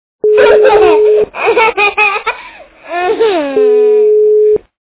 При прослушивании Маленький ребенок - Детский смех качество понижено и присутствуют гудки.
Звук Маленький ребенок - Детский смех